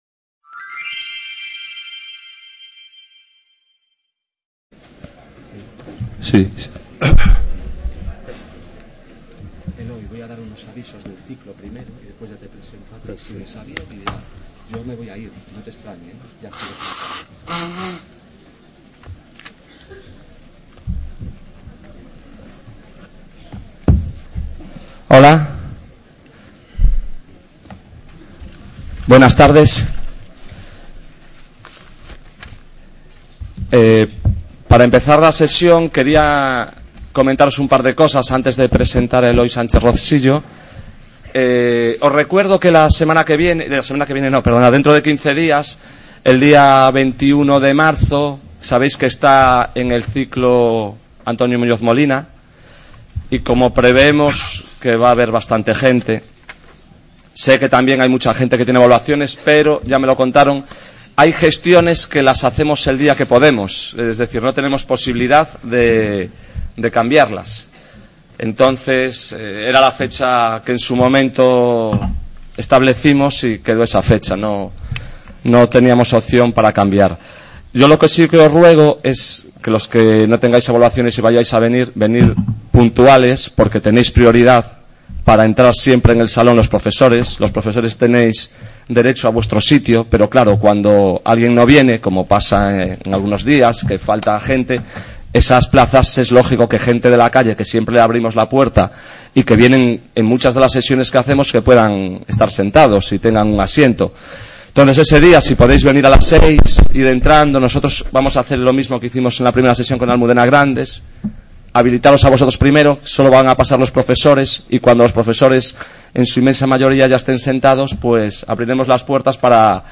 Charla con Eloy Sánchez Rosillo.